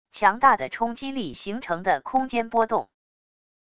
强大的冲击力形成的空间波动wav音频